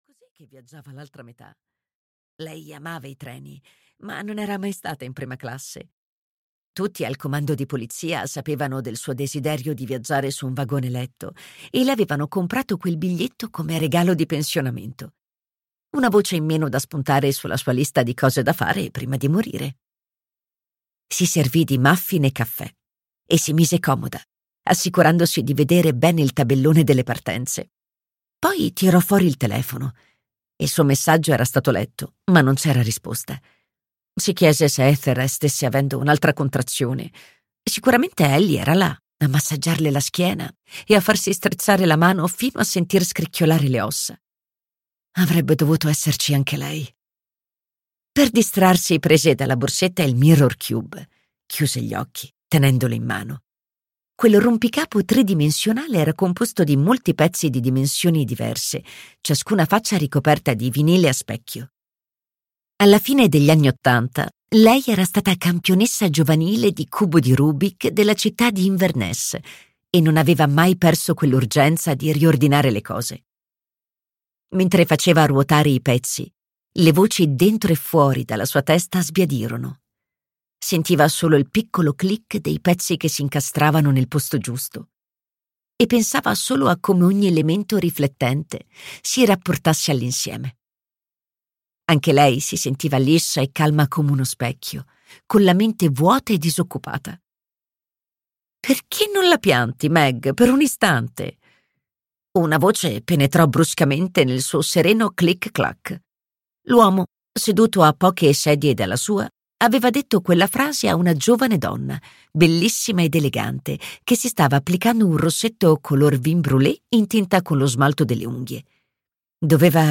"In treno con l'assassino" di Alexandra Benedict - Audiolibro digitale - AUDIOLIBRI LIQUIDI - Il Libraio